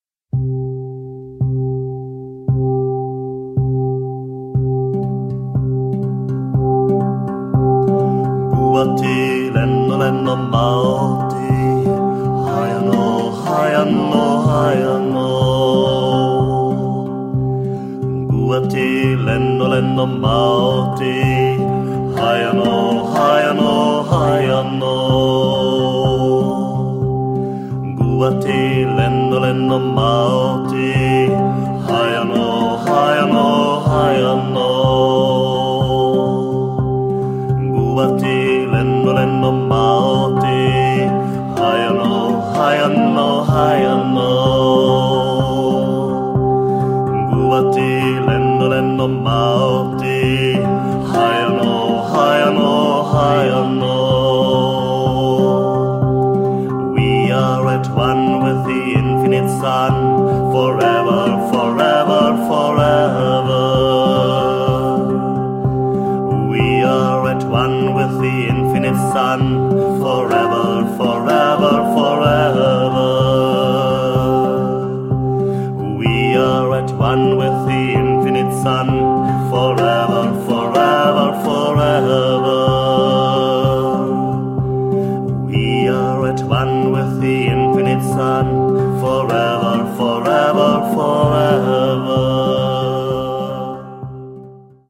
Er begleitet die Gesänge mit der Turtle Pan (Steel Harp).